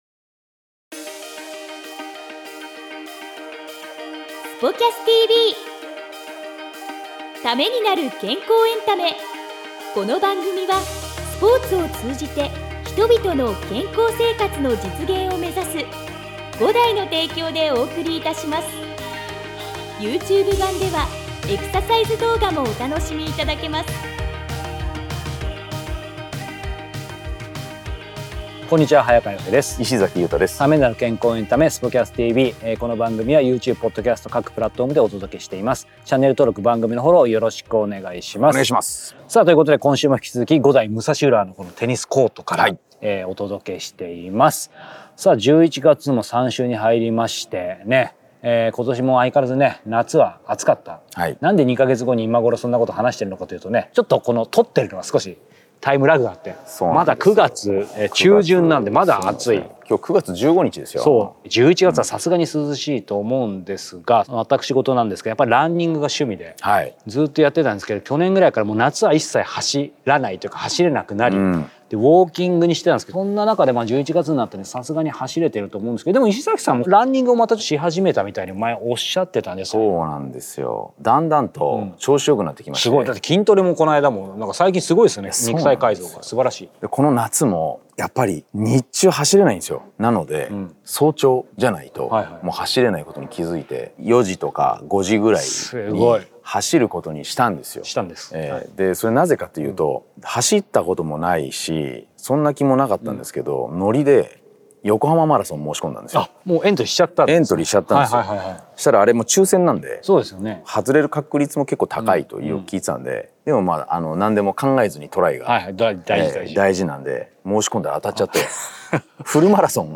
■日本代表監督として3年目を迎える添田豪さんが、決断を重ねる日々の舞台裏を語ります。責任ある立場で求められる「決断力」と、忙しい日々の中で大切にしている「健康とリフレッシュの習慣」。